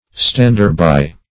Search Result for " stander-by" : The Collaborative International Dictionary of English v.0.48: Stander-by \Stand"er-by`\ (-b[imac]`), n. One who stands near; one who is present; a bystander.